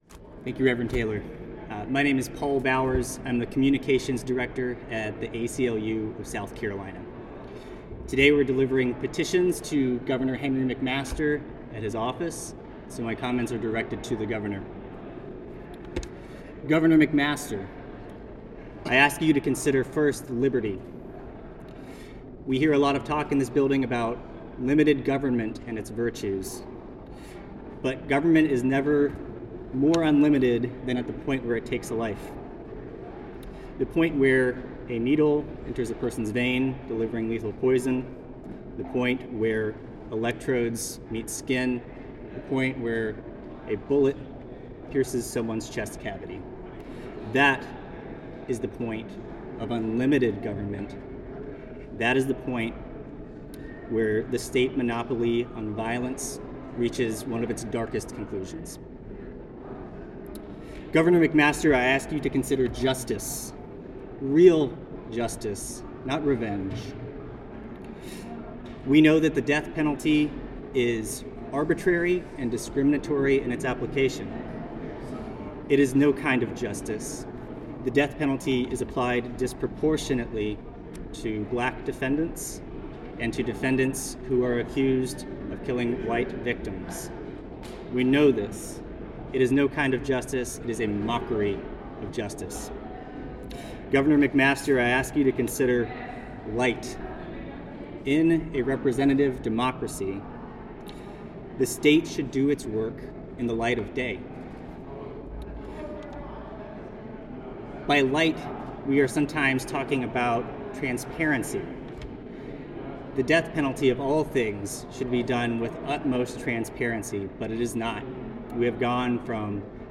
at a lectern in the lower lobby of the State House